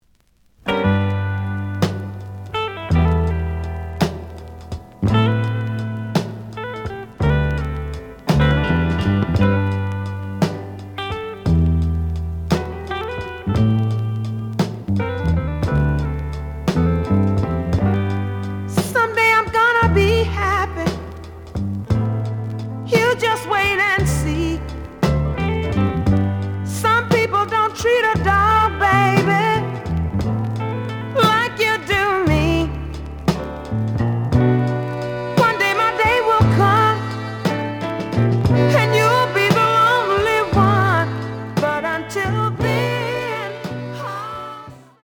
The audio sample is recorded from the actual item.
●Genre: Soul, 70's Soul
Edge warp.